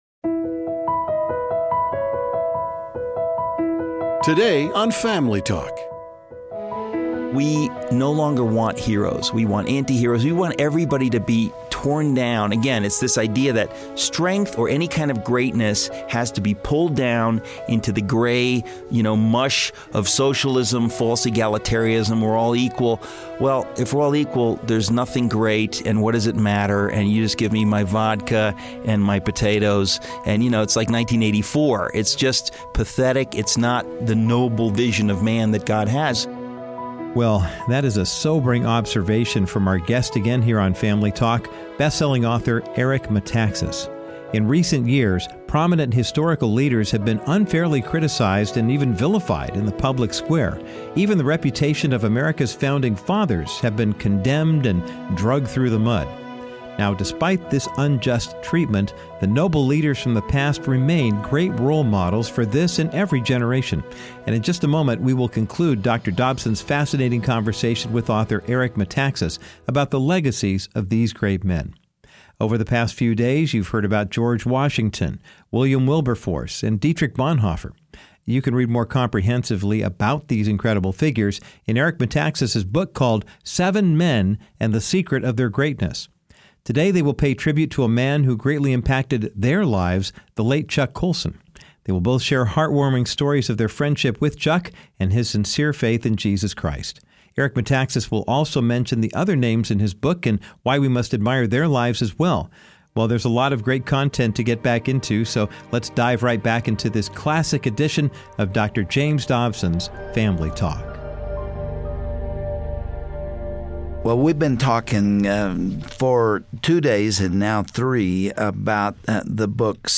Some of the most notable leaders from history remain the best role models of good character for this generation. Dr. Dobson concludes his discussion on this topic with New York Times best-selling author and radio host, Eric Metaxas.They focus on the integrity and influence of men like Chuck Colson, Eric Liddell and Jackie Robinson, who chose to follow God no matter what.